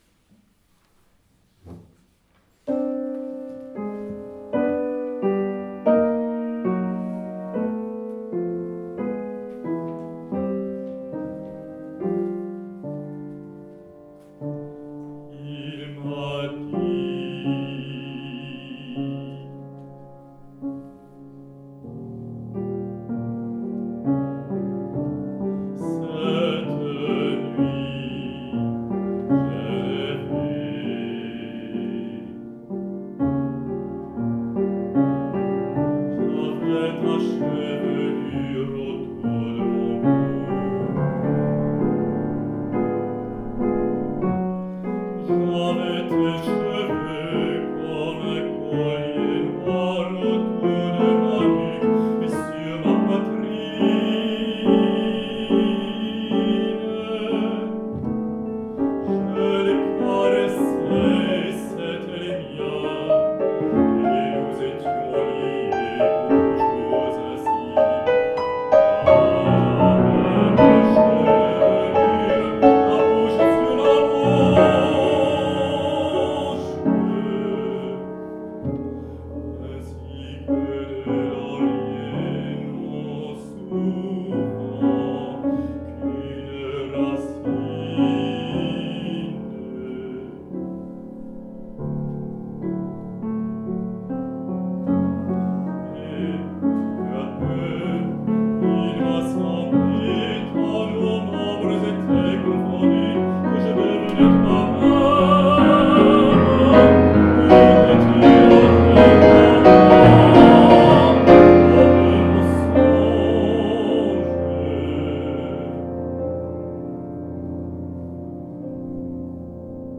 Debussy : La chevelure (Bariton